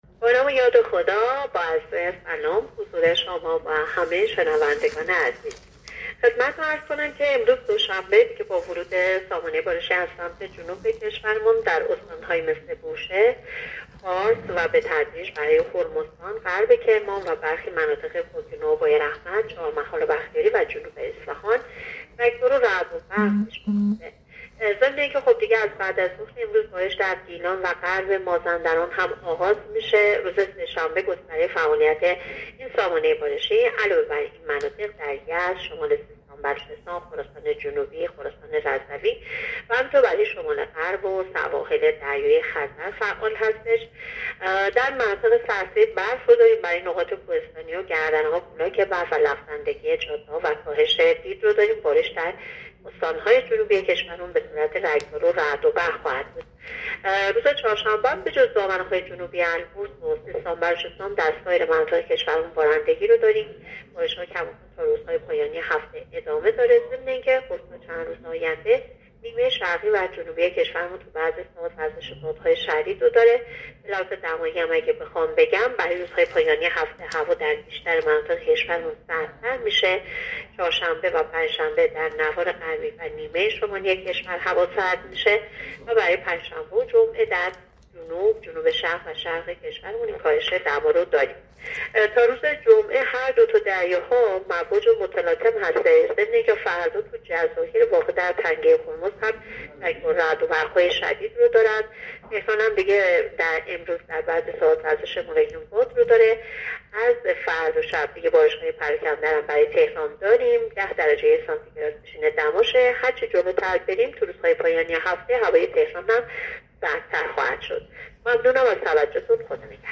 گزارش رادیو اینترنتی پایگاه‌ خبری از آخرین وضعیت آب‌وهوای ۲۴ آذر؛